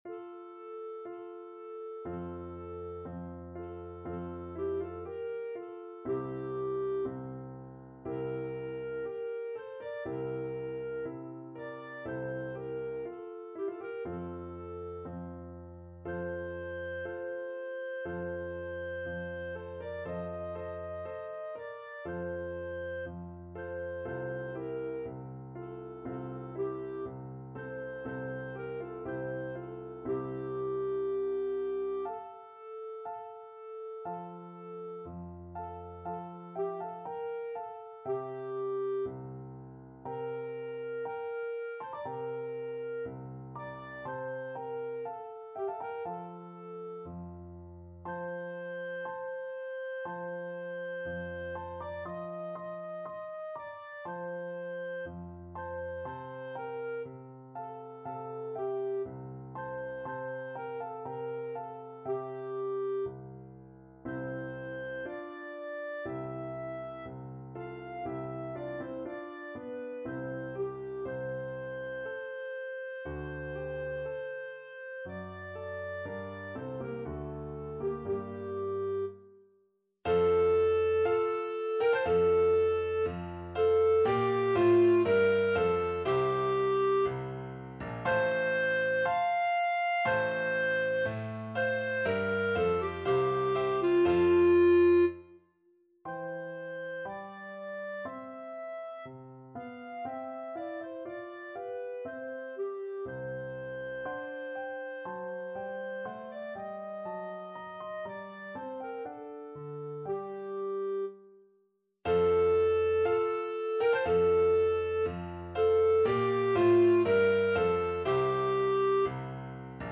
Free Sheet music for Clarinet
ClarinetClarinet
F major (Sounding Pitch) G major (Clarinet in Bb) (View more F major Music for Clarinet )
F5-F6
4/4 (View more 4/4 Music)
Slow =c.60
Classical (View more Classical Clarinet Music)